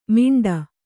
♪ miṇḍa